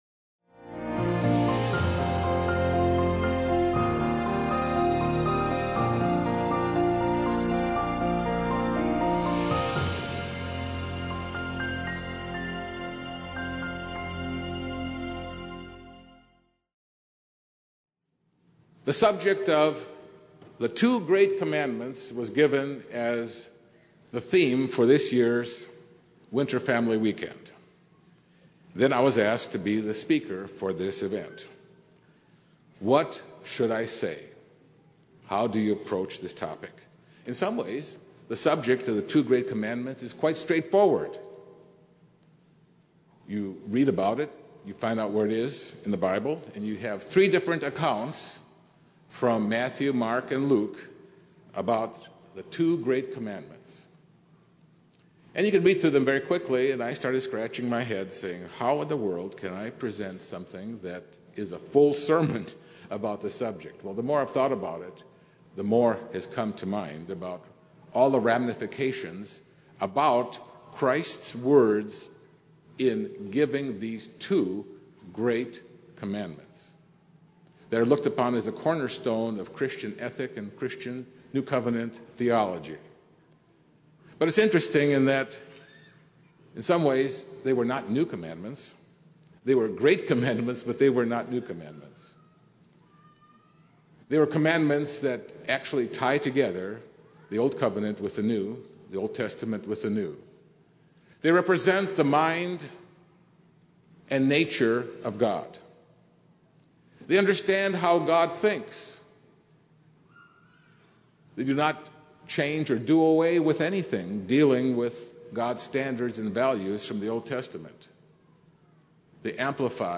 This sermon was given during the 2013 Winter Family Weekend. The two great commandments reveal a great deal about God.